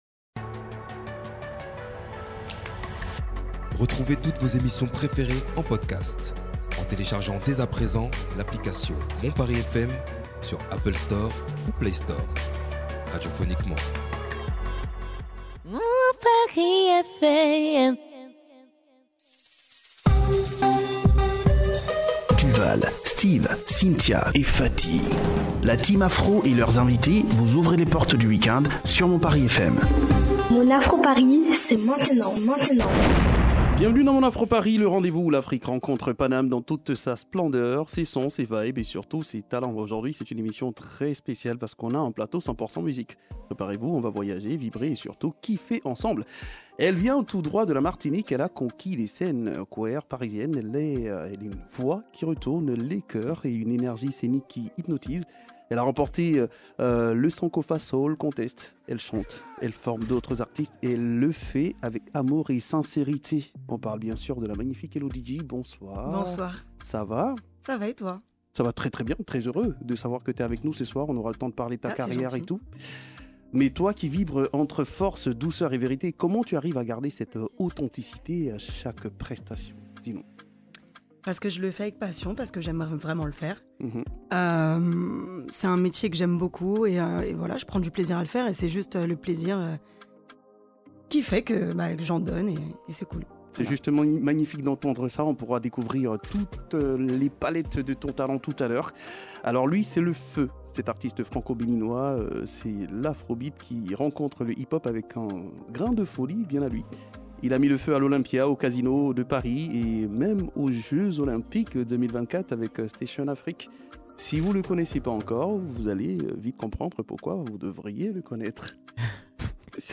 Un plateau 100% musique avec des artistes qui ont des univers différents.
Du reggae à l'afrobeat en passant par la soul et la rumba.